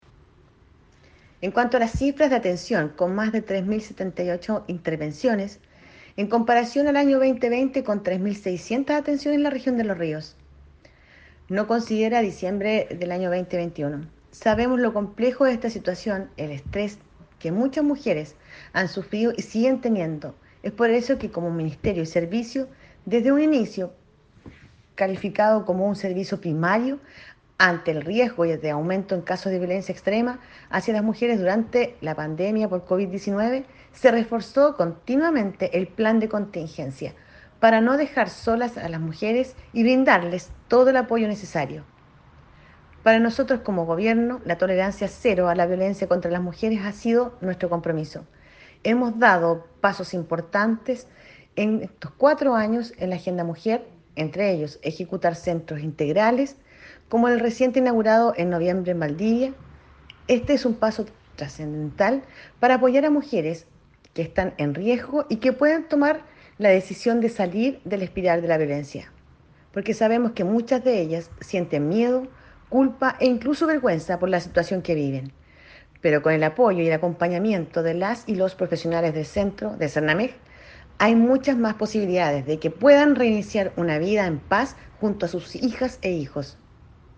REPORTAJE-CUÑA-02-DIRECTORA-SERNAMEG-Unidad-de-Violencia-contra-la-Mujer.mp3